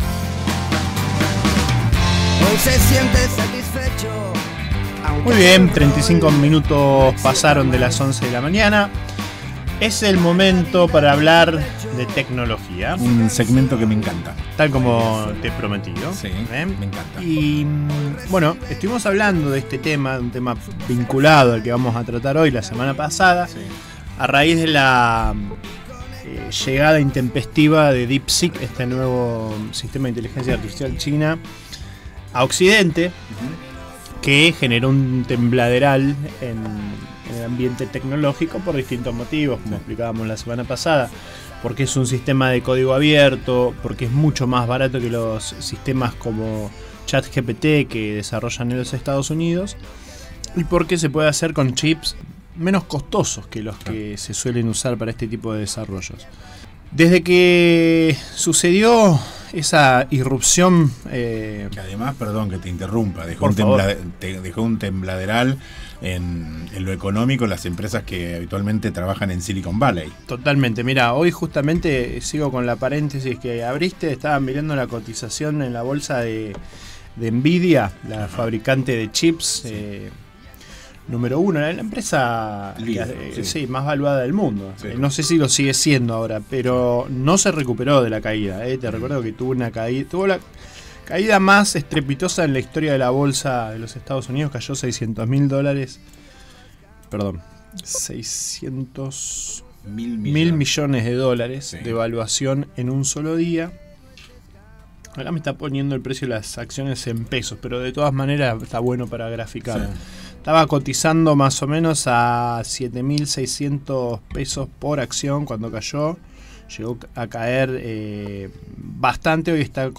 Tercera Entrevista en Radio Con Vos Patagonia: comienzan a aplicarse las regulaciones sobre IA en la Unión Europea, en medio de tensiones entre China y EE.UU.